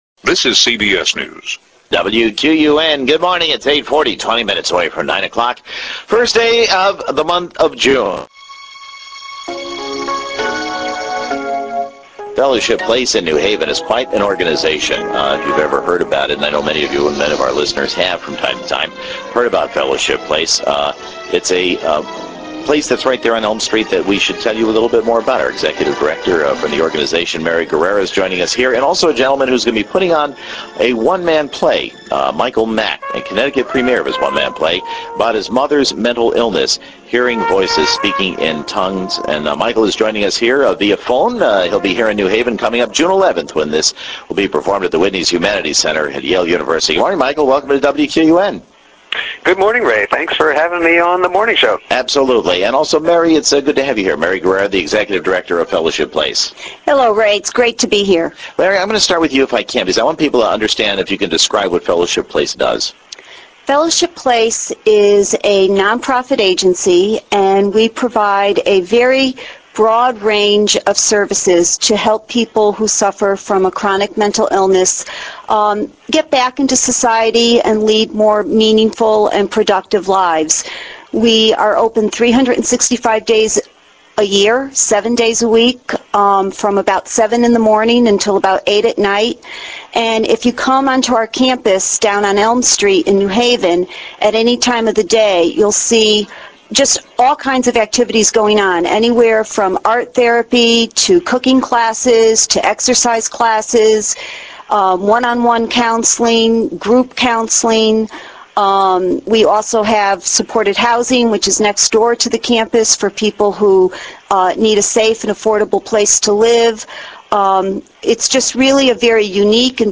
Hear interview from CBS Radio News station WQUN (mp3, 12:25) .